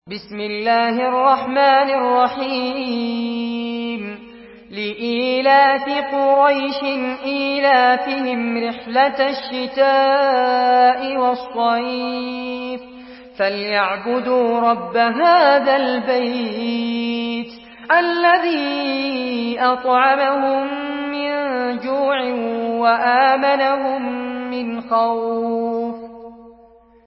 سورة قريش MP3 بصوت فارس عباد برواية حفص عن عاصم، استمع وحمّل التلاوة كاملة بصيغة MP3 عبر روابط مباشرة وسريعة على الجوال، مع إمكانية التحميل بجودات متعددة.
مرتل حفص عن عاصم